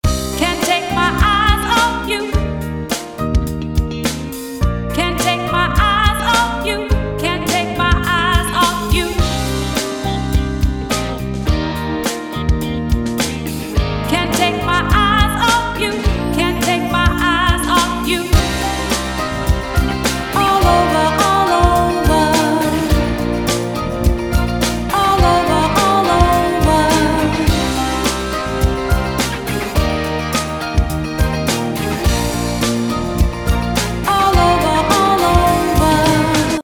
Für das nächste Demo kamen wiederum Ueberschall-Loops zum Einsatz, nämlich aus den Libraries Pop Ballads und The Voice. Den Gesang habe ich mit einer Effektkonstellation von Slate Digital aufpoliert und dann einen Rob Papen Reverb dezent beigemischt.
Zunächst ohne den Drawmer 1973 …